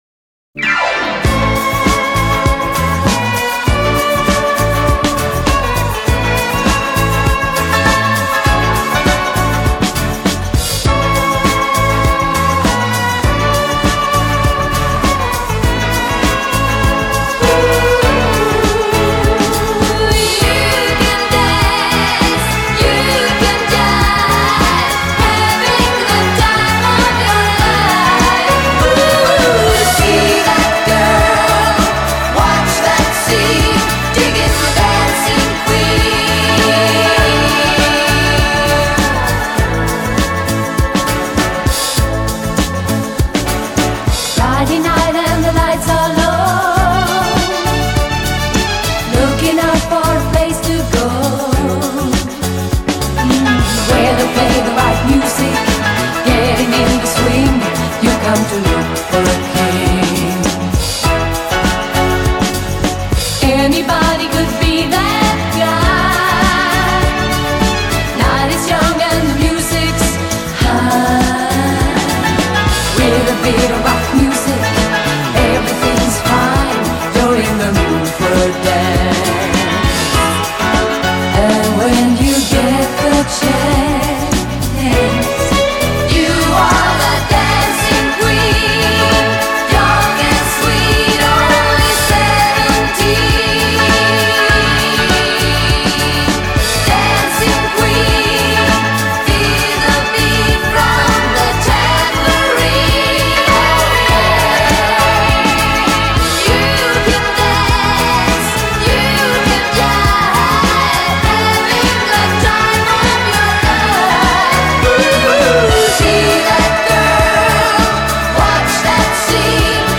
Projekt Chor HochMeisterVocale
Registerproben in der Kirche